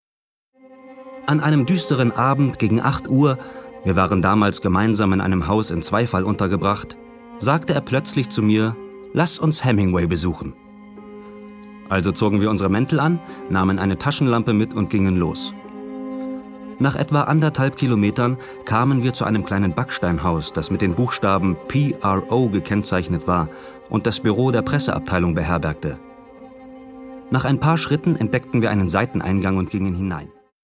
sehr variabel, dunkel, sonor, souverän
Mittel minus (25-45)
Doku